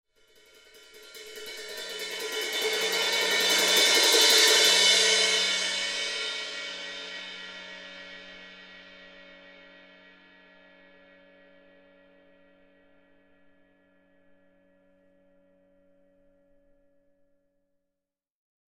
Звуки барабанных тарелок
Напряженная минута